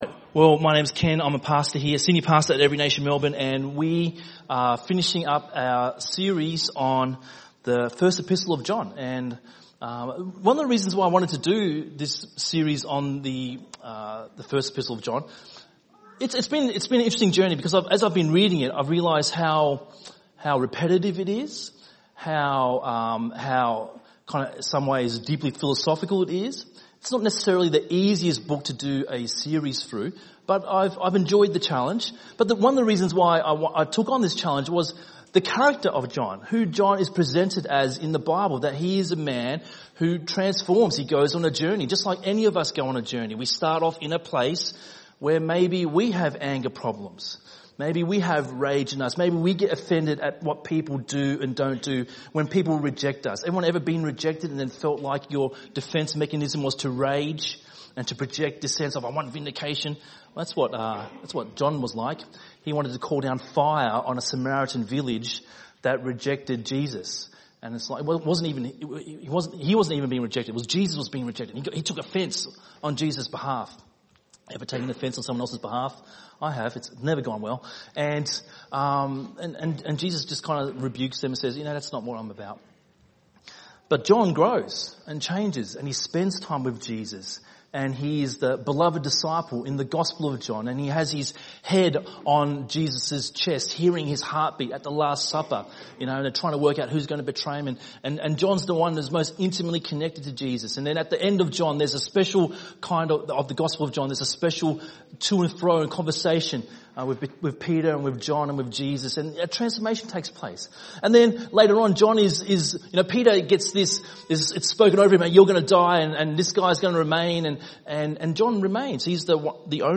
by enmelbourne | Nov 11, 2019 | ENM Sermon